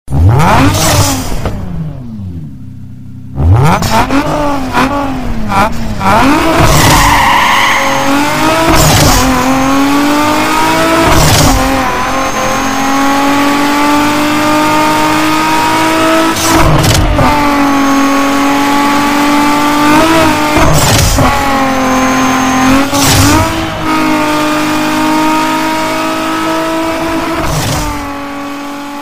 need-for-speed-turbo-power_25219.mp3